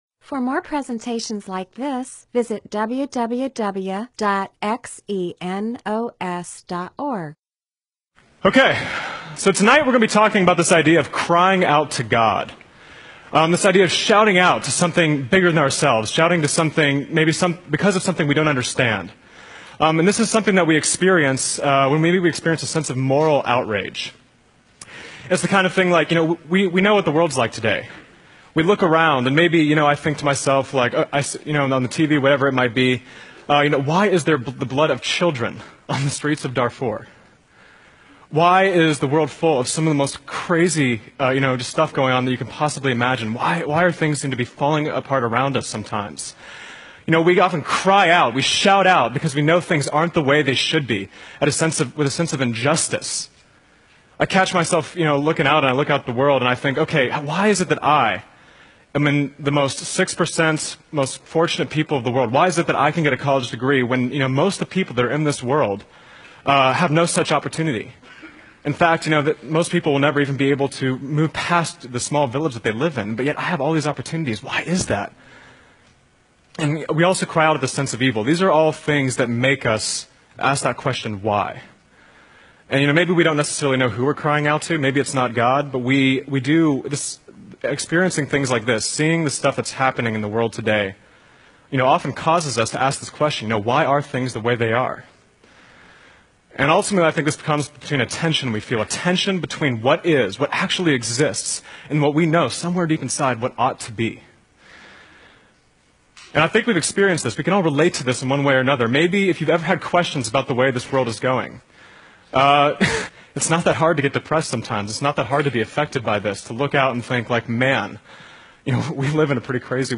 MP4/M4A audio recording of a Bible teaching/sermon/presentation about Habakkuk 1-3.